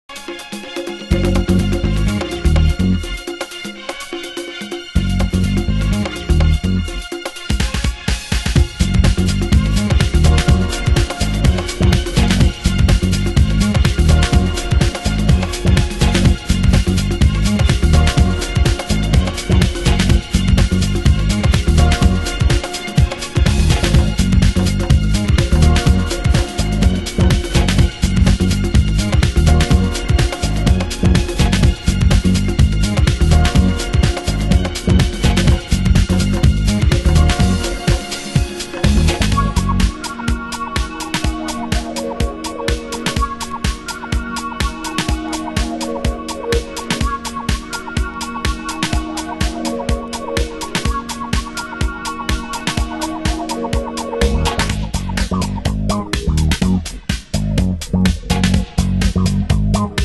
盤質：イントロ等に少しチリノイズ有